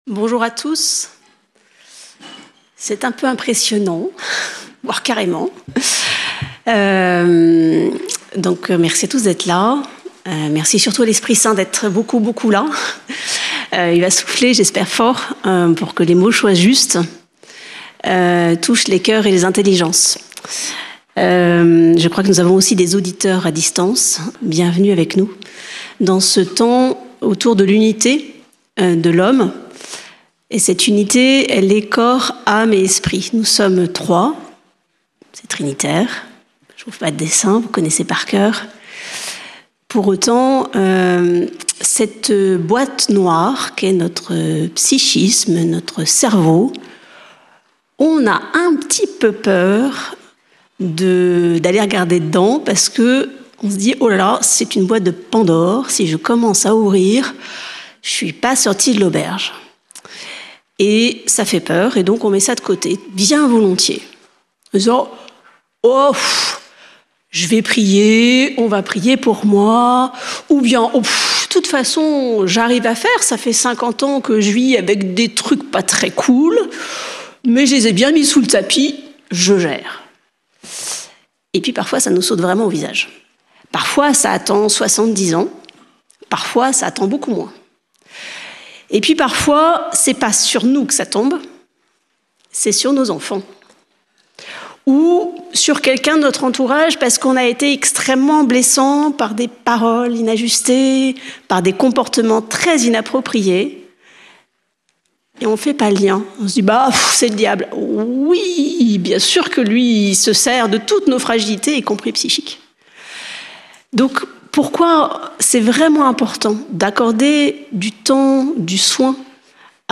Notre Dame du Laus - Festival Marial